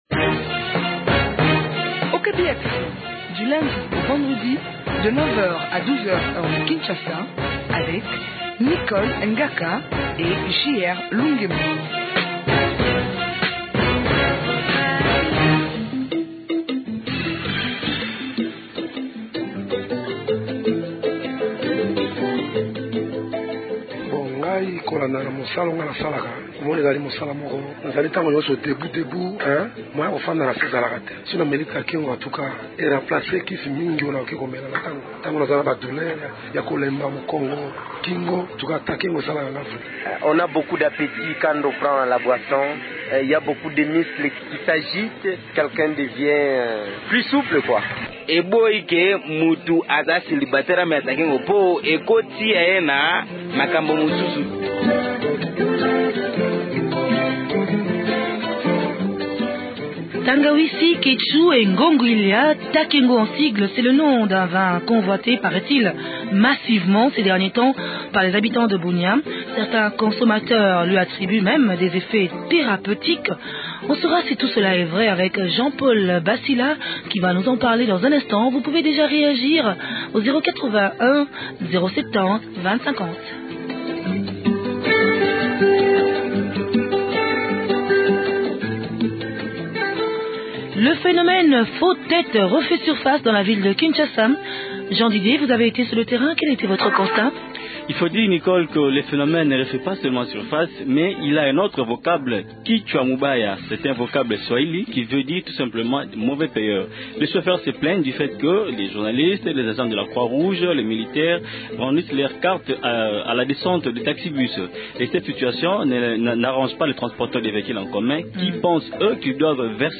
Il repond aux questions